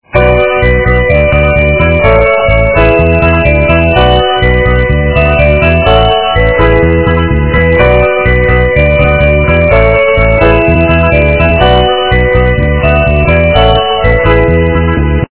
- западная эстрада
качество понижено и присутствуют гудки